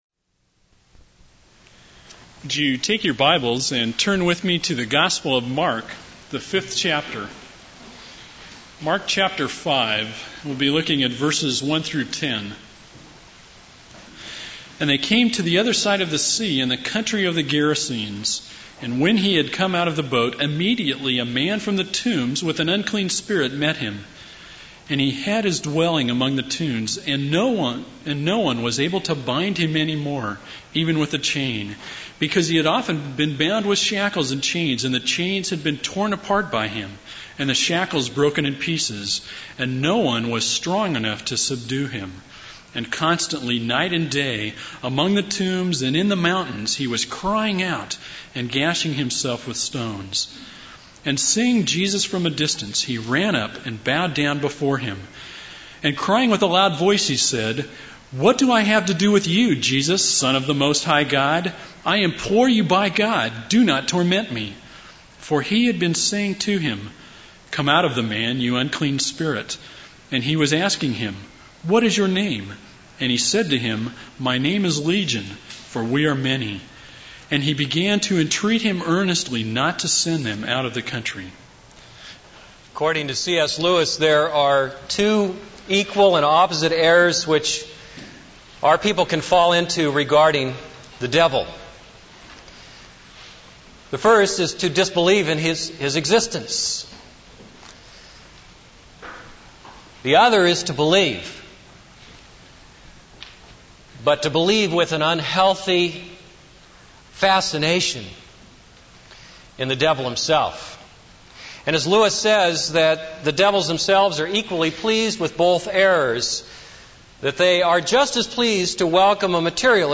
This is a sermon on Mark 5:1-20.